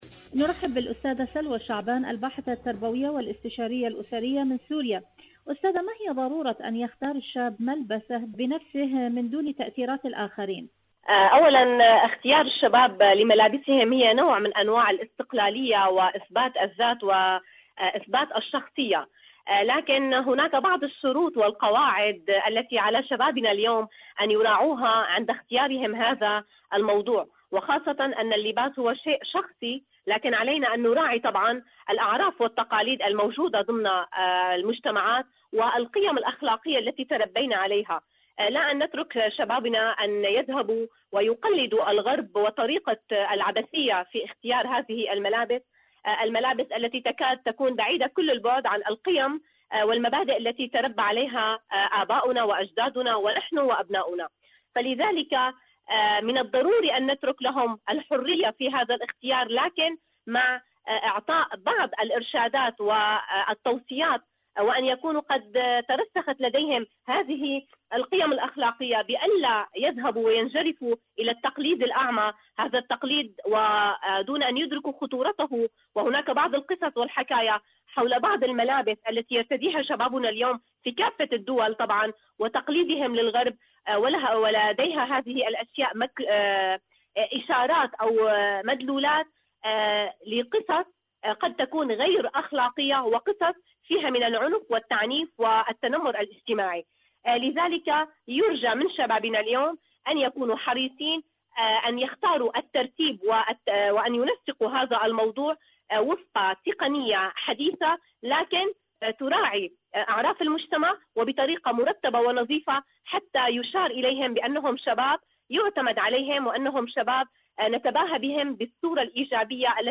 إذاعة طهران-دنيا الشباب: مقابلة إذاعية